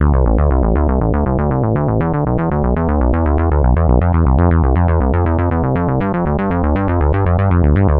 三重低音1
描述：一个具有"shuffling"感觉的贝司。 1 of 3
Tag: 120 bpm Electro Loops Bass Loops 1.35 MB wav Key : D